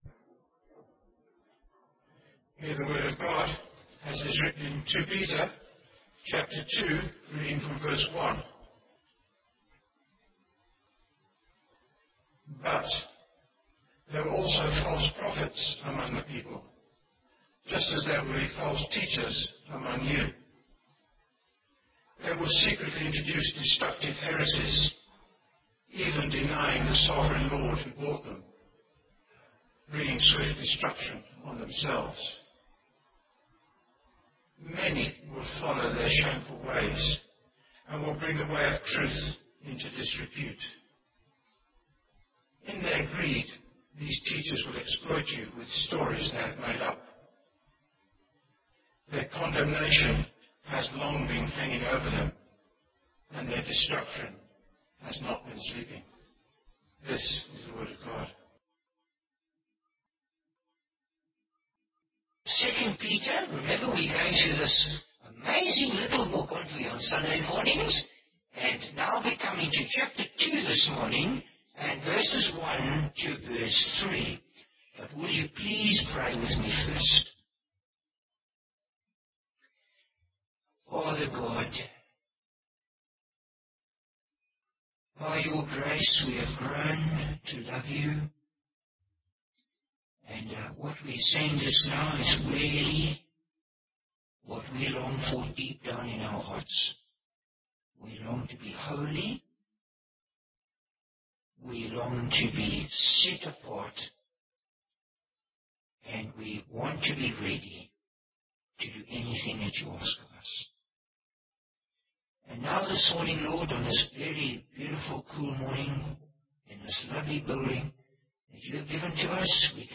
Bible Text: 2 Peter 2: 1-3 | Preacher: Bishop Warwick Cole-Edwards | Series: 2 Peter